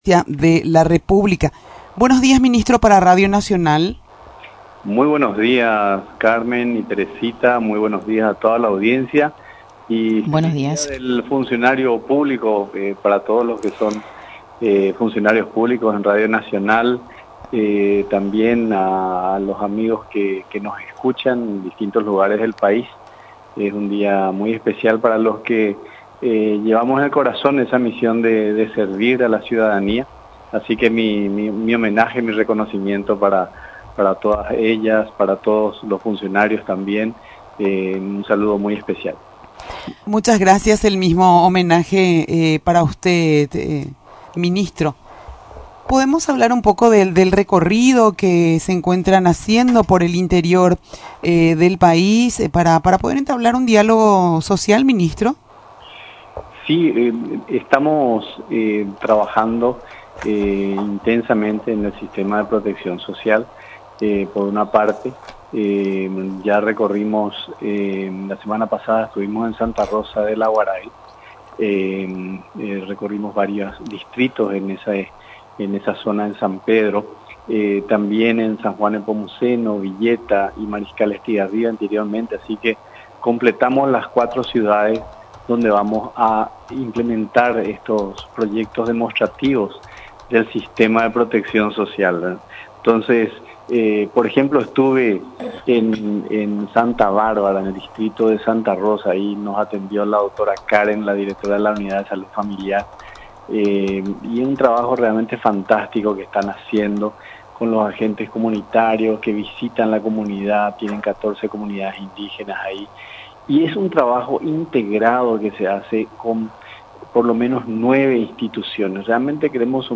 El ministro de Gestión Presidencial, Hugo Cáceres, comentó acerca del Sistema de Protección Social, que se pensó para beneficiar a las familias paraguayas.